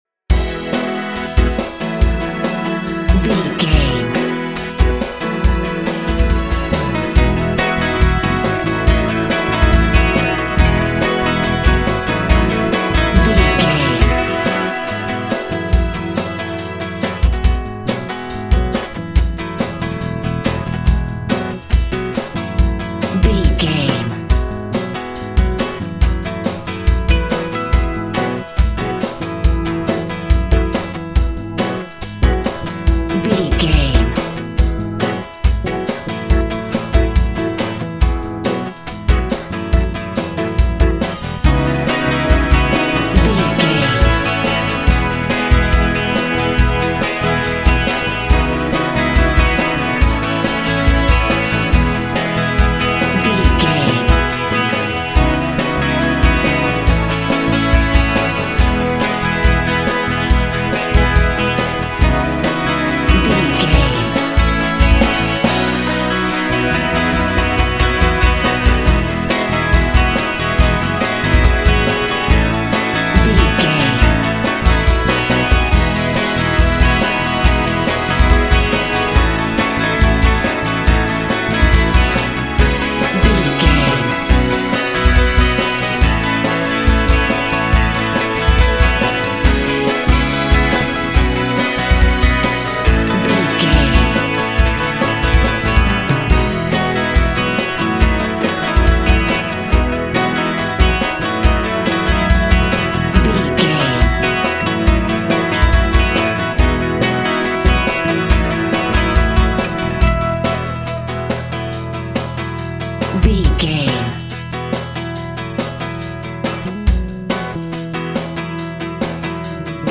Ionian/Major
cheesy
pop rock
synth pop
brit pop music
drums
bass guitar
electric guitar
piano
hammond organ